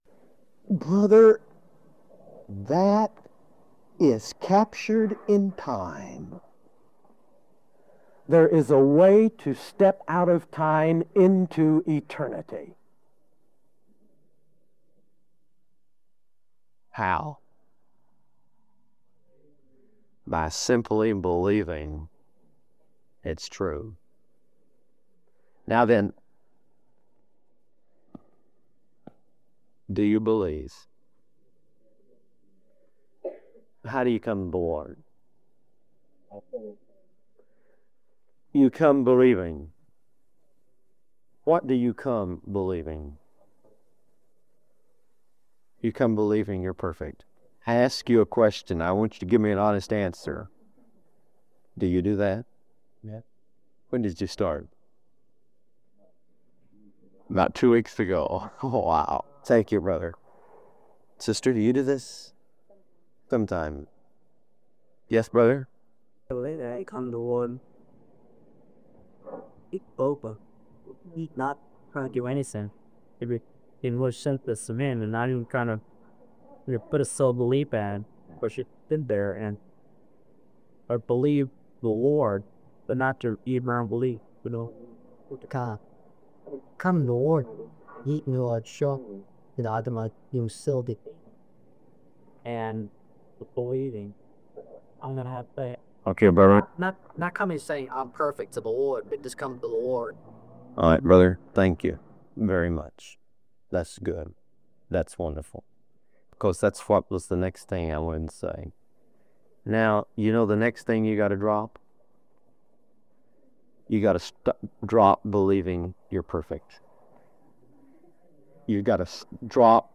Church in Isla Vista, CA